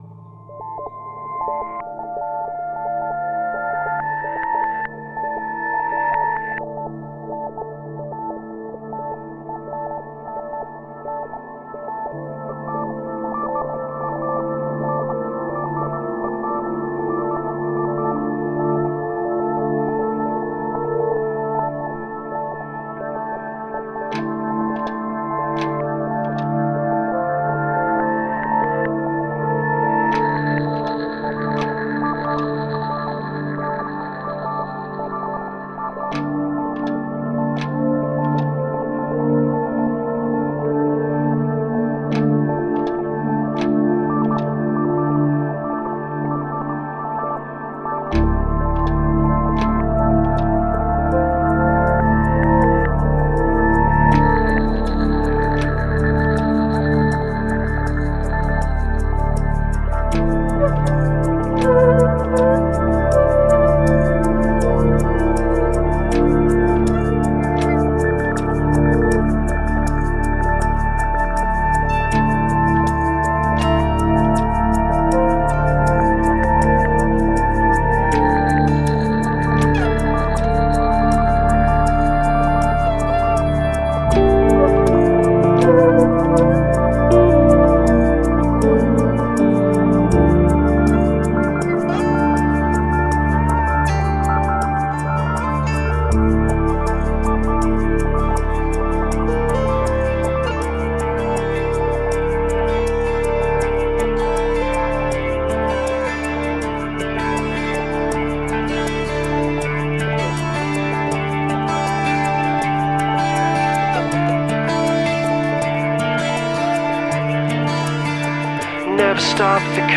Progressive Rock, Progressive Metal